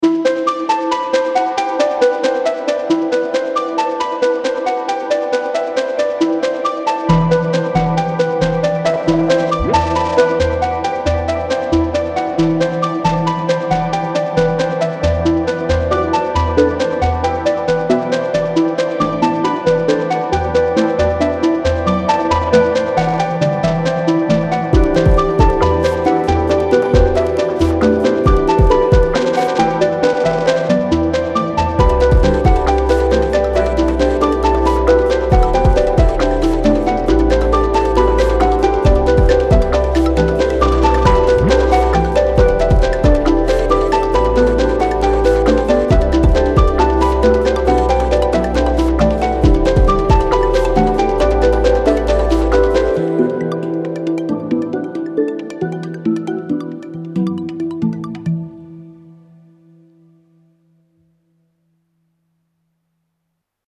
Pop Score